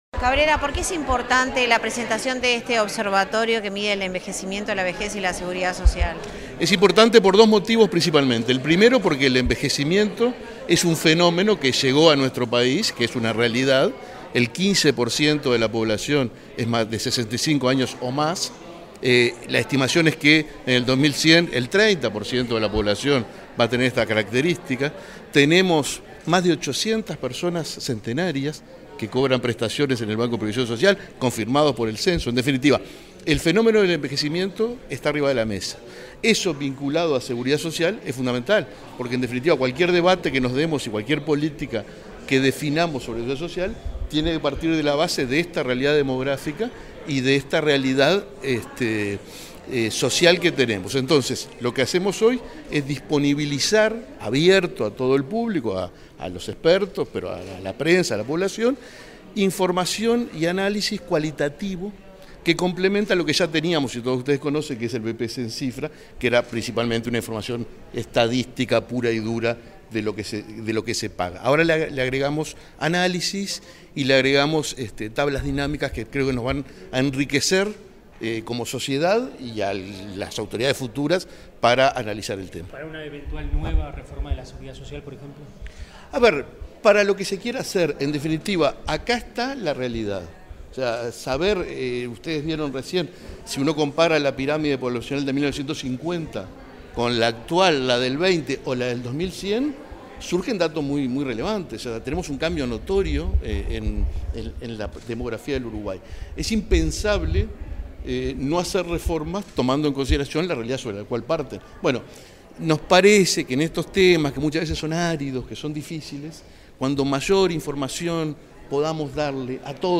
Declaraciones del presidente del BPS, Alfredo Cabrera
Tras la presentación del Observatorio de Vejez, Envejecimiento y Seguridad Social, este 4 de febrero, el presidente del Banco de Previsión Social (BPS
Declaraciones del presidente del BPS, Alfredo Cabrera 04/02/2025 Compartir Facebook X Copiar enlace WhatsApp LinkedIn Tras la presentación del Observatorio de Vejez, Envejecimiento y Seguridad Social, este 4 de febrero, el presidente del Banco de Previsión Social (BPS), Alfredo Cabrera, dialogó con los medios informativos presentes.